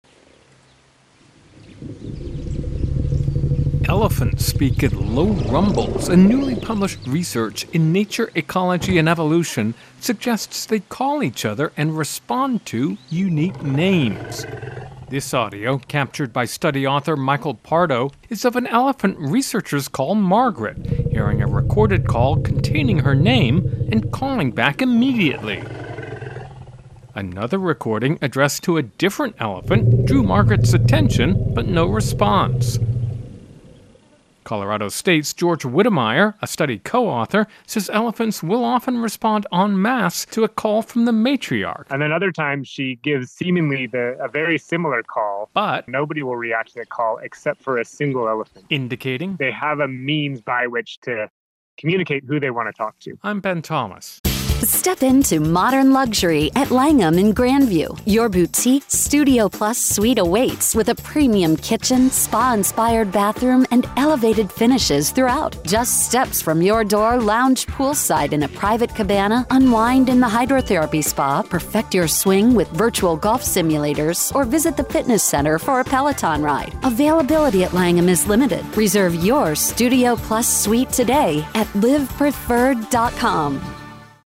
((Opens with elephant sounds))